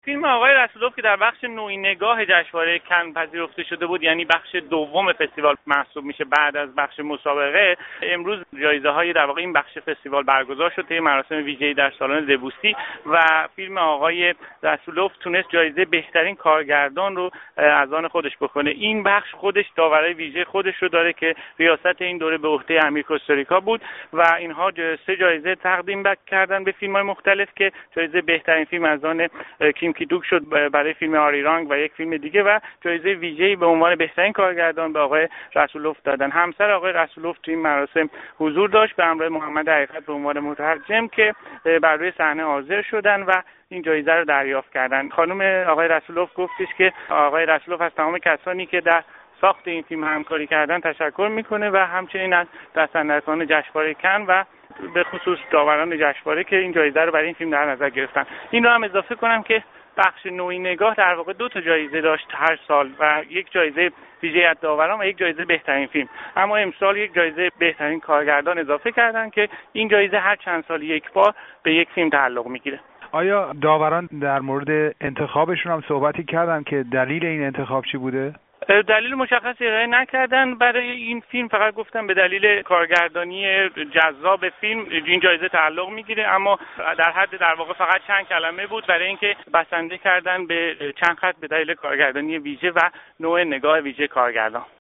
گفت و گوی